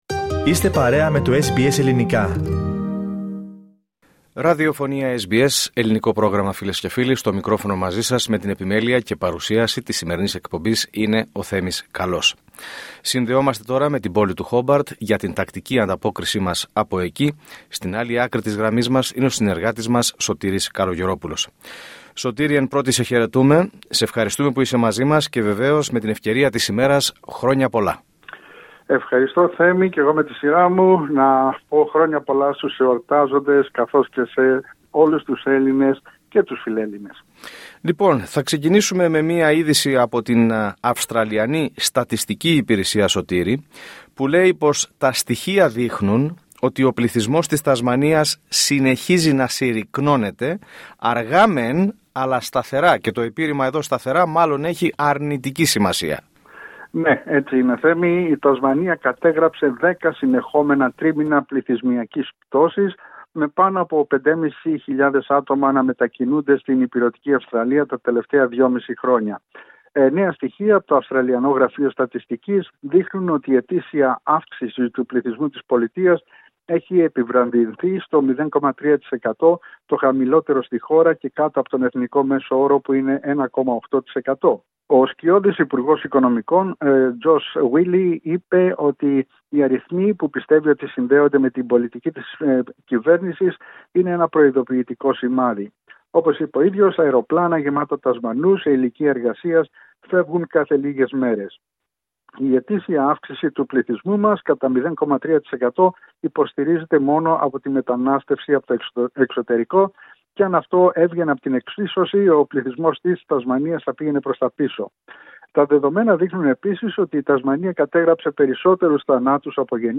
Ακούστε την εβδομαδιαία ανταπόκριση από την Τασμανία